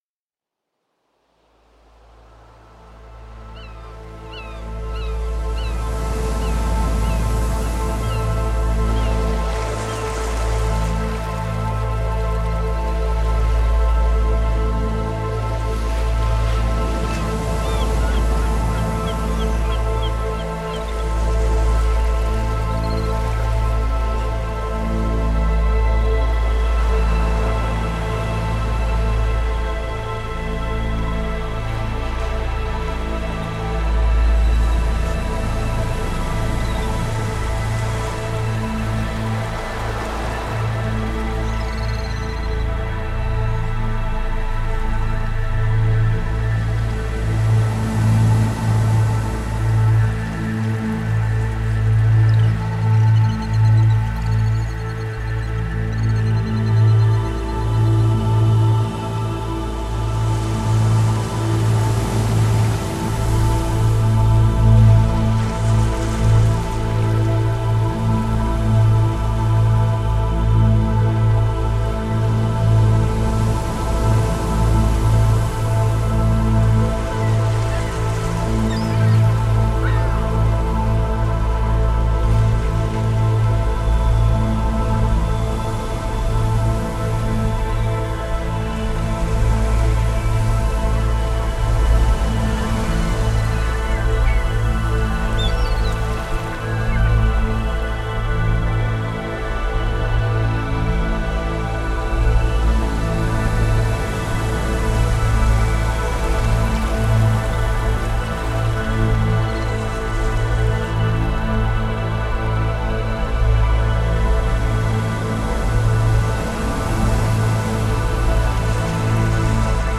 Healing, meditative ambient new age.
Tagged as: Ambient, New Age, Space Music